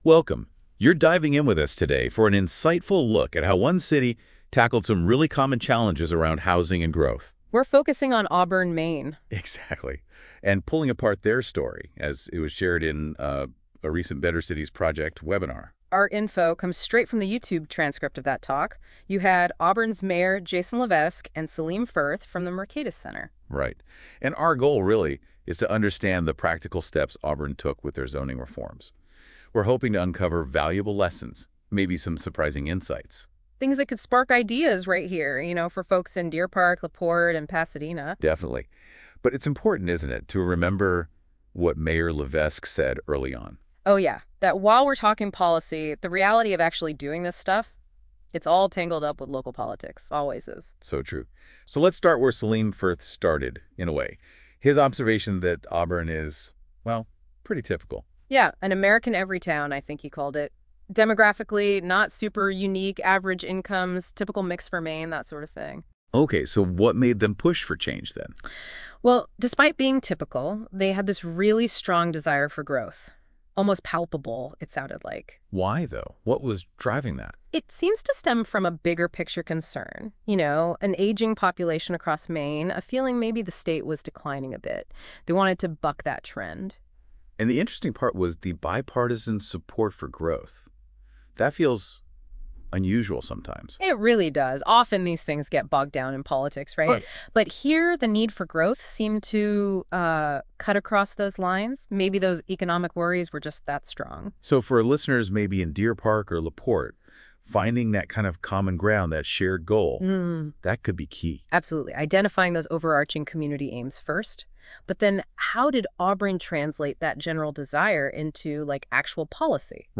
🏘 Auburn Maine Zoning Reform: A Better Cities Project Webinar #425